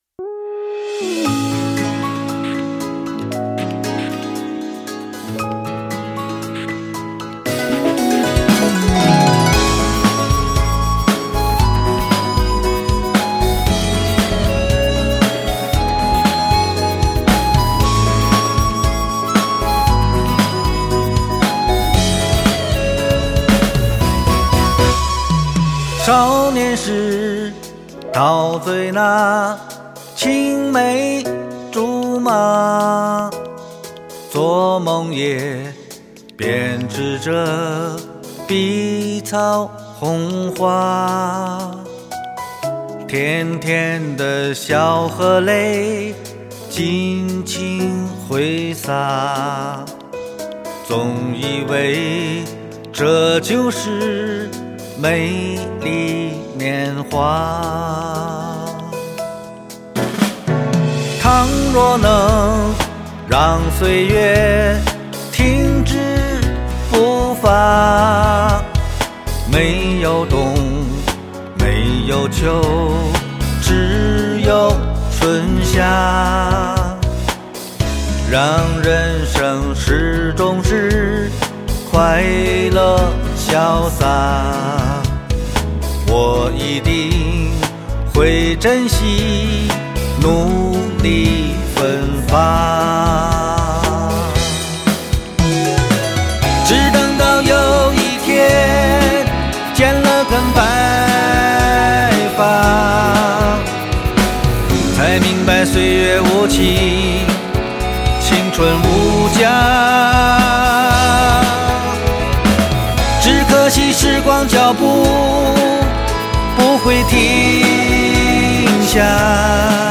依然的叙事体民谣风，从旋律到编曲到演唱